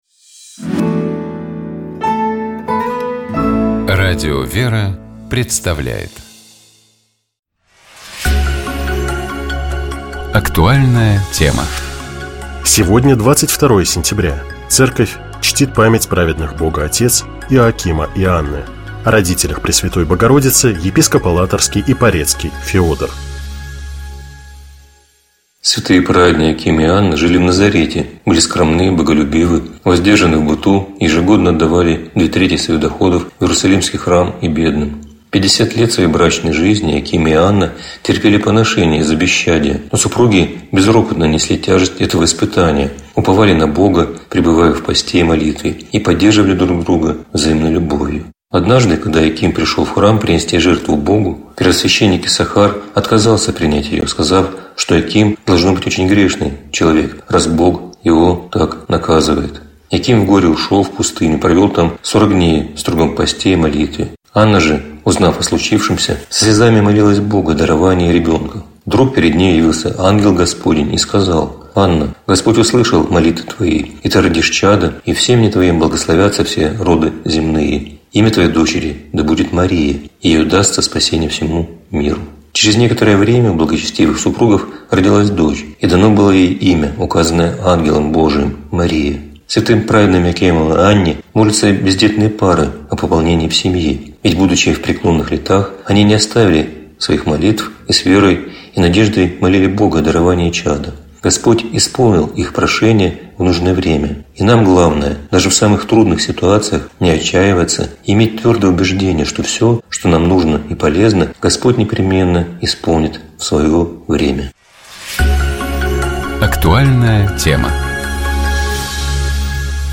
О родителях Пресвятой Богородицы, — епископ Алатырский и Порецкий Феодор.